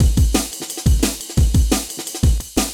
peacebewithyouamen.wav